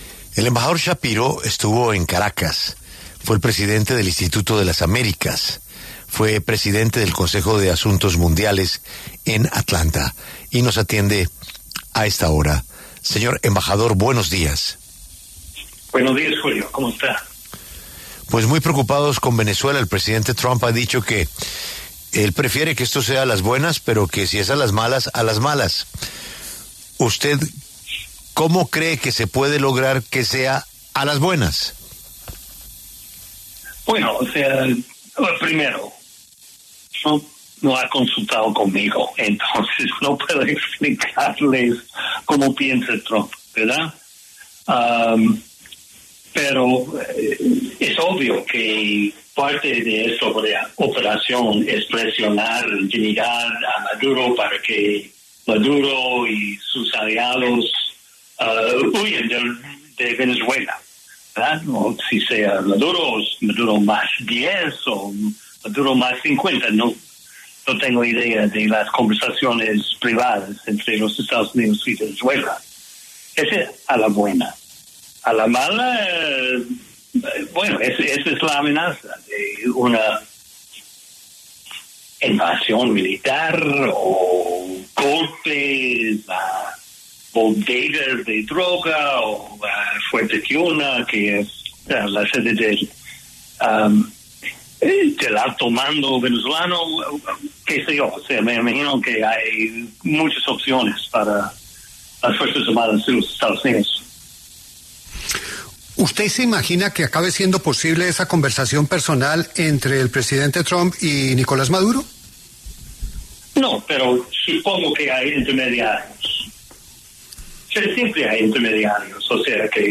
Charles Shapiro, diplomático estadounidense y exembajador en Venezuela, pasó por los micrófonos de La W, con Julio Sánchez Cristo, para hablar acerca de la tensión diplomática y militar entre Estados Unidos y Venezuela.